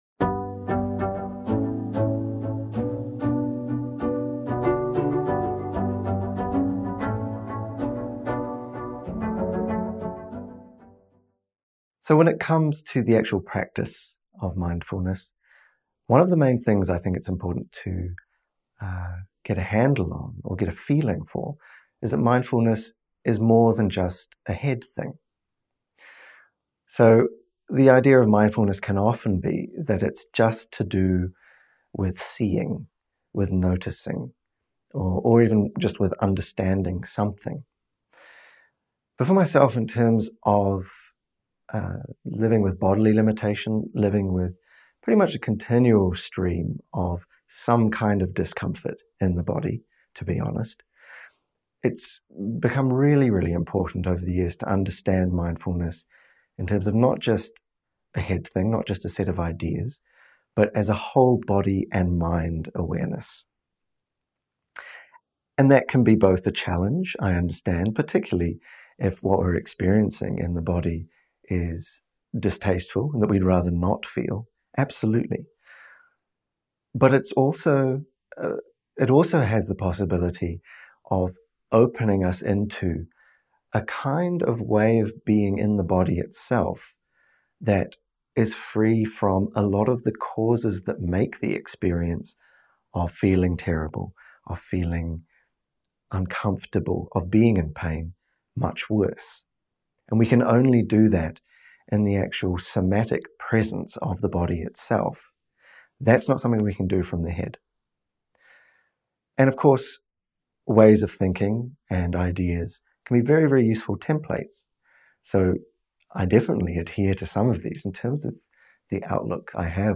Talk: Somatic Awareness for Opening the Heart